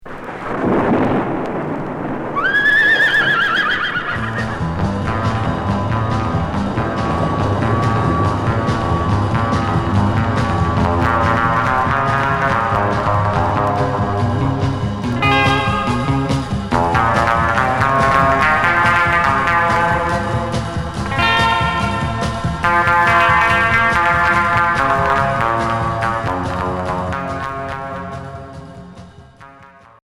Rock surf